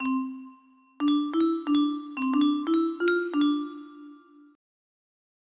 Musekbox_template music box melody